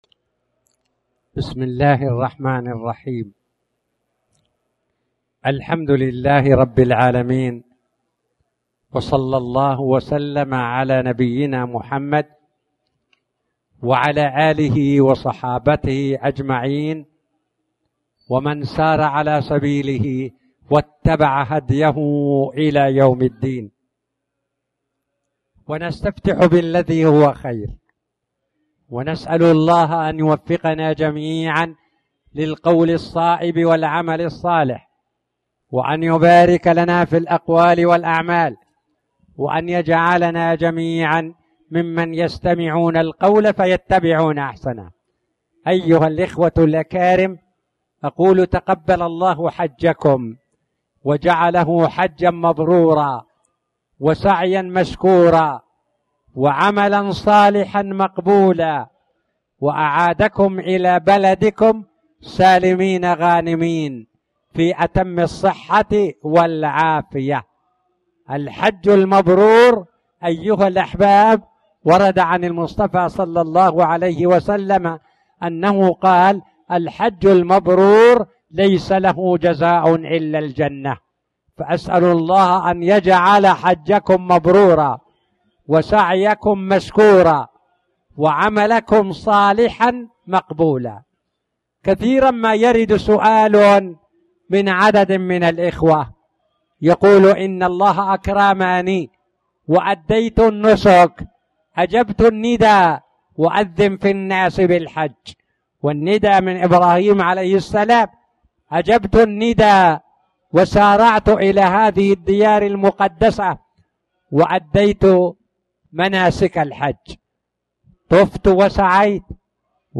تاريخ النشر ٢١ ذو القعدة ١٤٣٨ هـ المكان: المسجد الحرام الشيخ